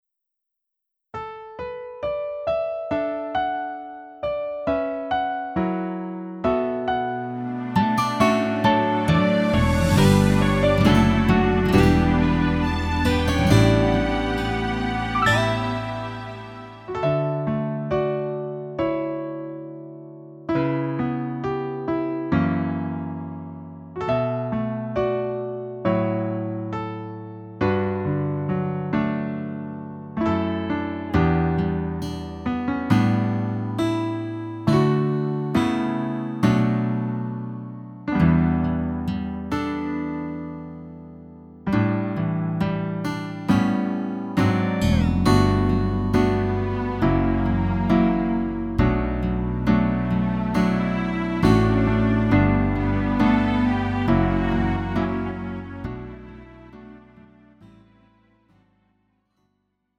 음정 -1키 4:37
장르 가요 구분 Lite MR